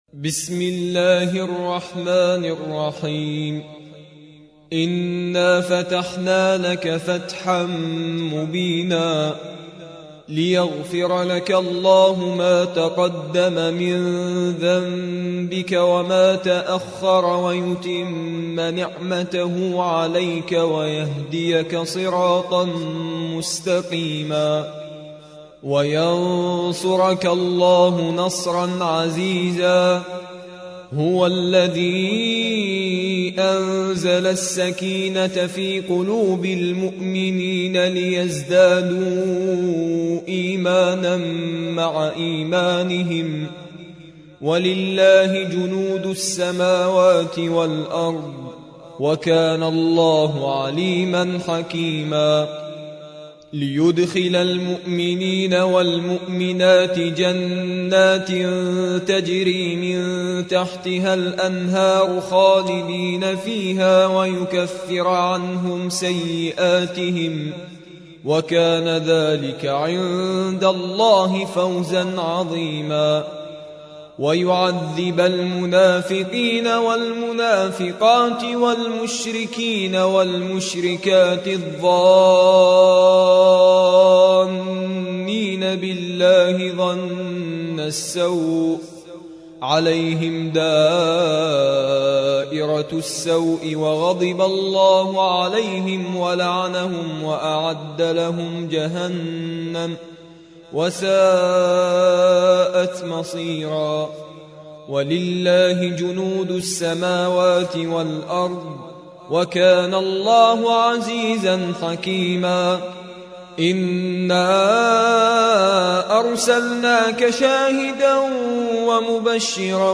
48. سورة الفتح / القارئ